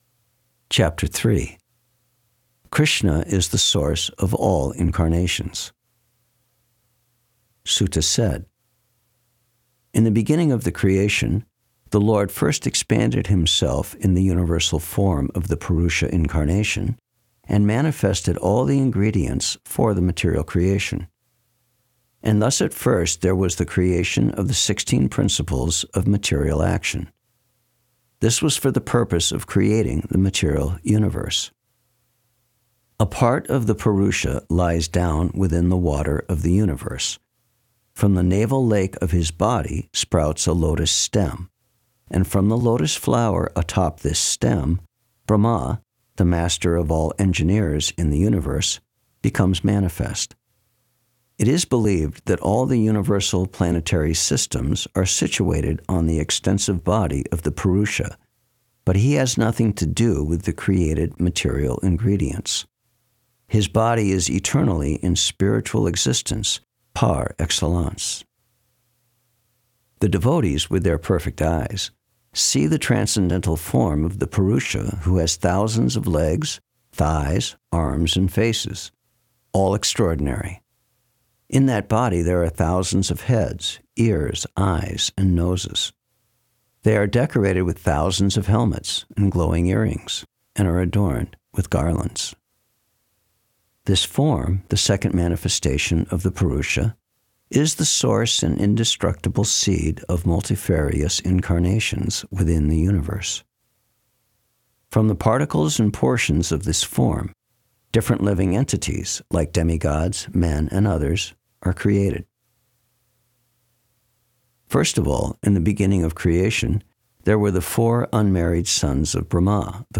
Thank you very much for this clear reading =) Really appreciate it.
Ch_03_SB_1st_Canto_Verses_Only.mp3